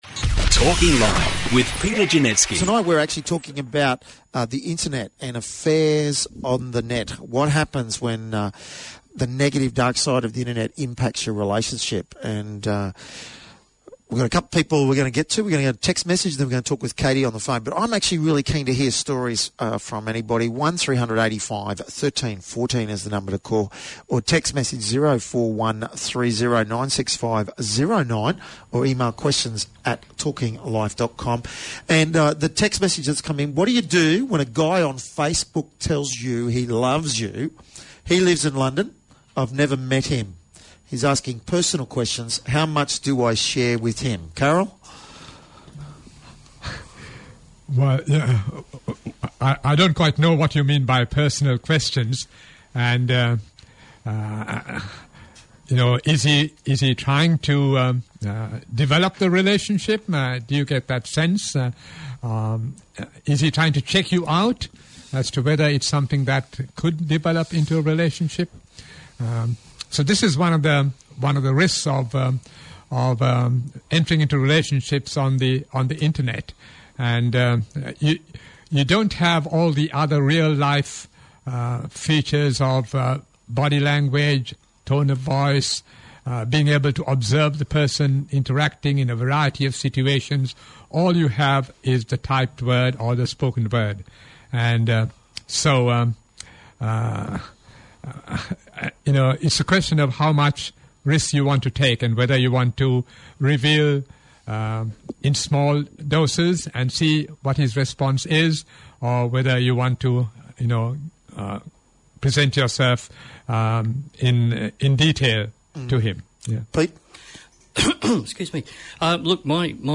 The Internet, Facebook, Pornography & Affairs: A Talkback Podcast Of Real-Life Stories
The discussion, which involved a number of stories from listeners calling in, was about the darker side of the internet and social networking. A number of people spoke of the tragic circumstances of losing their relationships through affairs, in which Facebook and other social networking sites where involved.